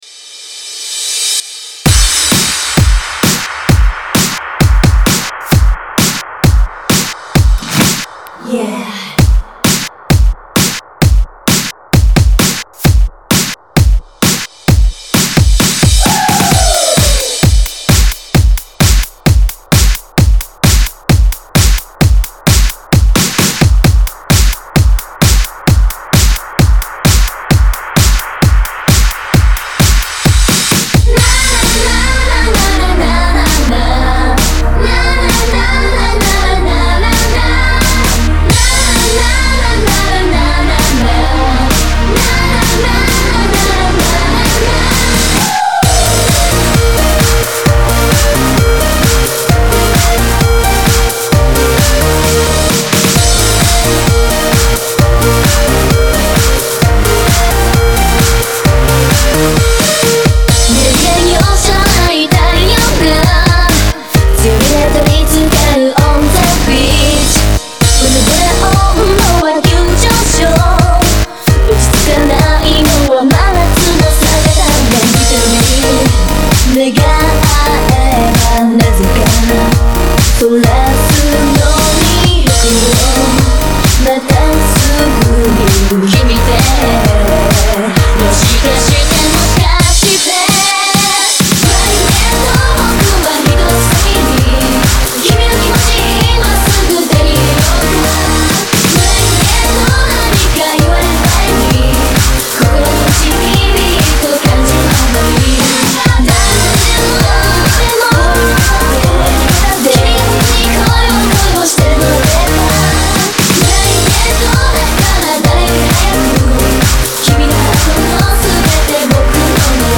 Genre(s): House